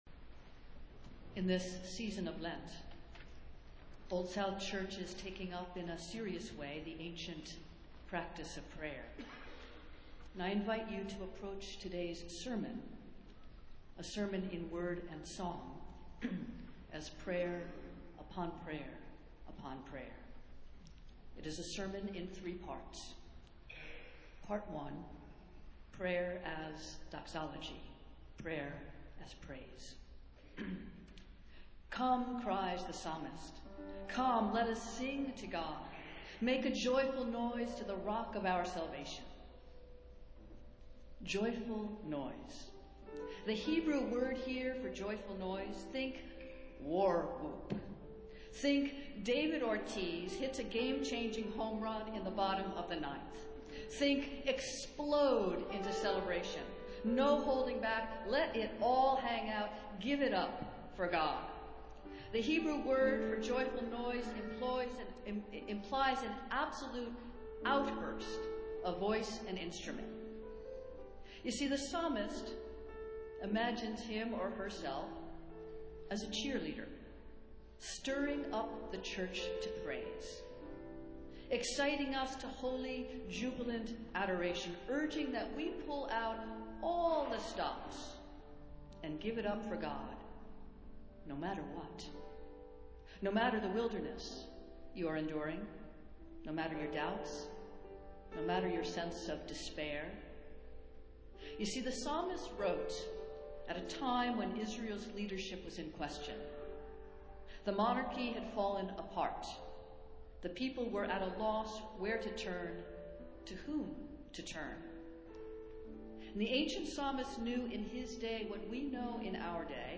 A Sermon in Word and Song